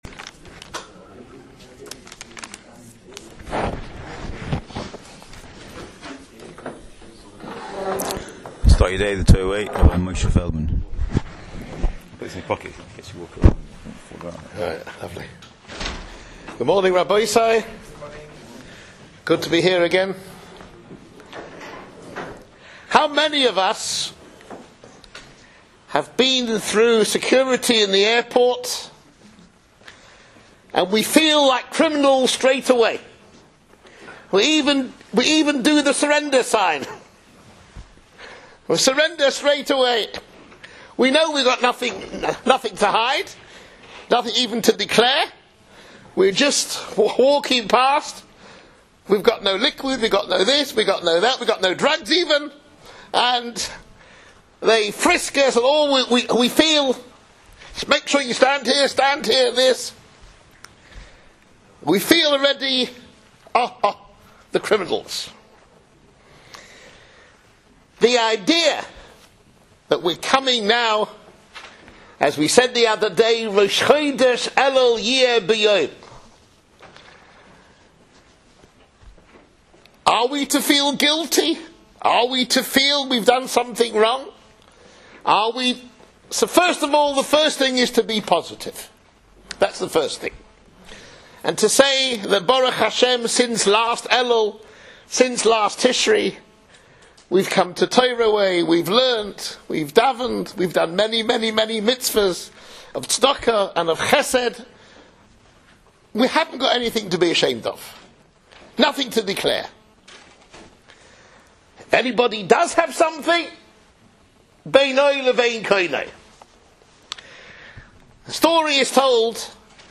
shiur